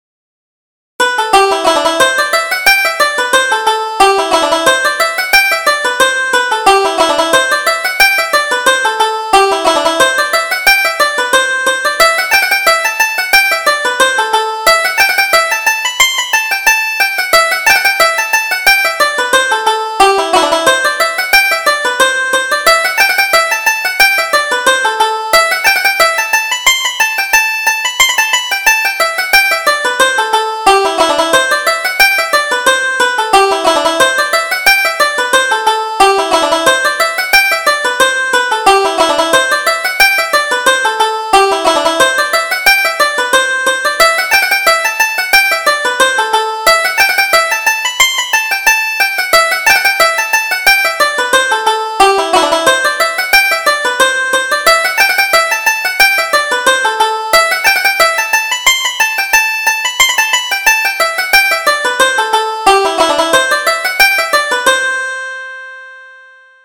Reel: Cunningham's Fancy